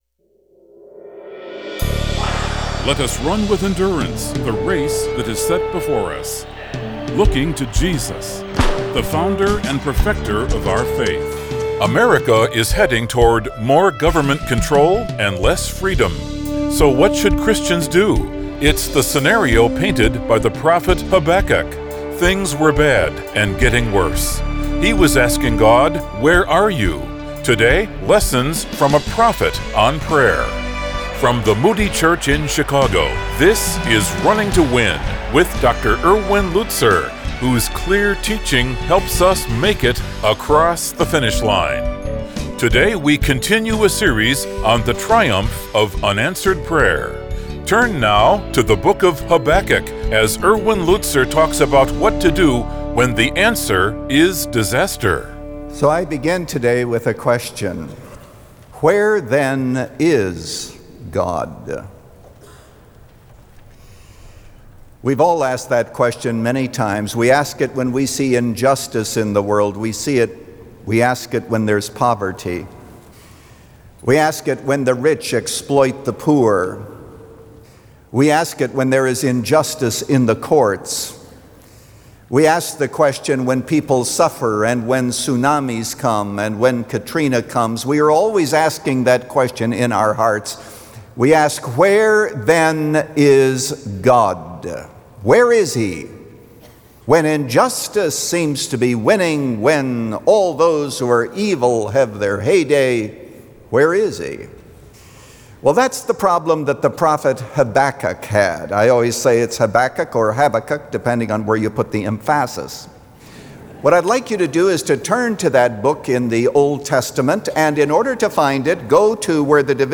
When The Answer Is Disaster – Part 1 of 4 | Radio Programs | Running to Win - 15 Minutes | Moody Church Media